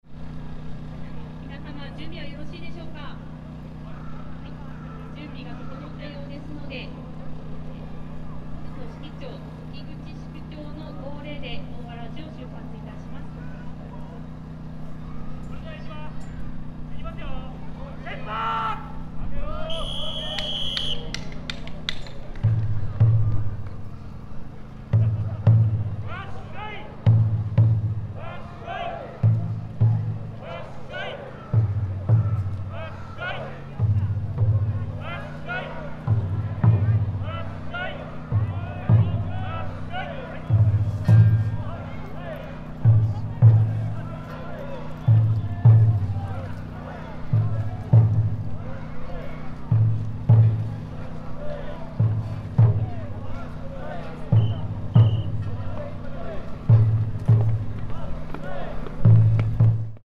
Komayama Park at the bottom of Mt. Shinobu Park
On the Shinobu-Sanzan Akatsuki Mairi Festival (Visiting the Shinto shrine at Mt. Shinobu at dawn), the parade of O-waraji (big sandal dedicated to Haguro Shrine, located at the top of Mt. Shinobu) was held.
Regarding the changes in sound compared to last year, the sound of the conch shell was not heard in this year.